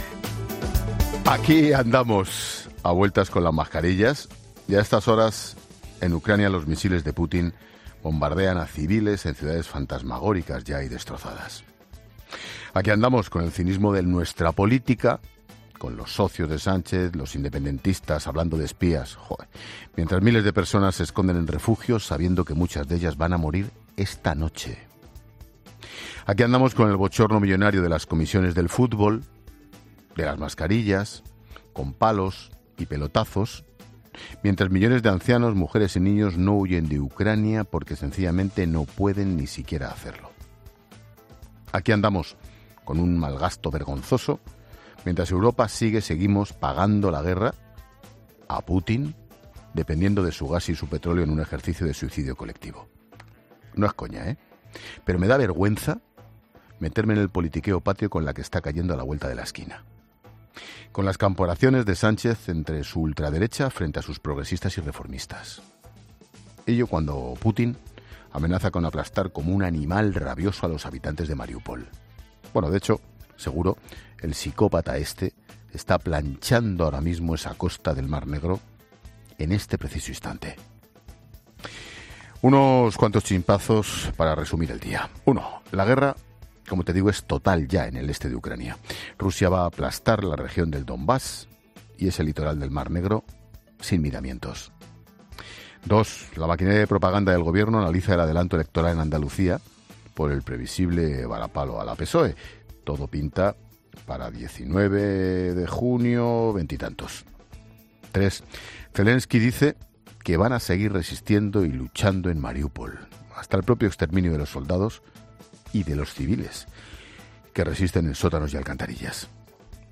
Monólogo de Expósito
El director de 'La Linterna', Ángel Expósito, desgrana las principales claves del día y agradece la fidelidad de sus oyentes tras el EGM